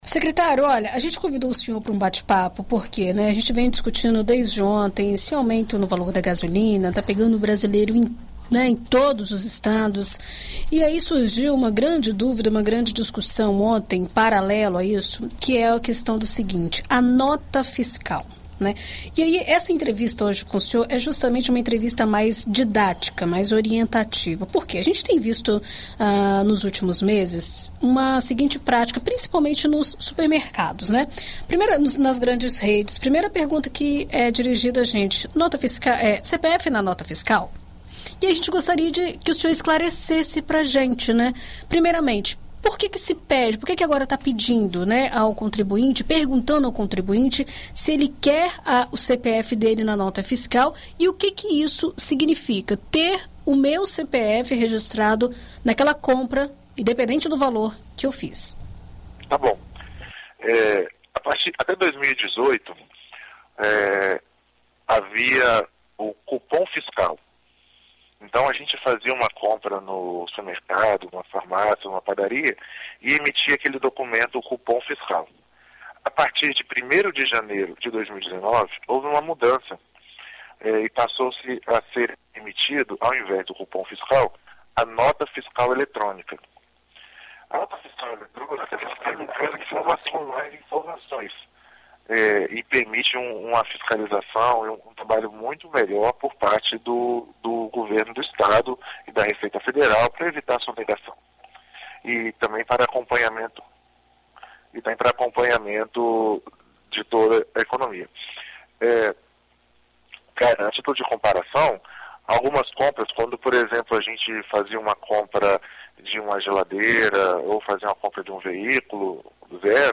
A partir do mês de junho, o Espírito Santo passará a adotar um programa de incentivo à emissão de nota fiscal com prêmio aos consumidores. É o que contou o secretário de Estado da Fazenda, Rogelio Amorim, à BandNews FM Espírito Santo nesta quinta-feira (25).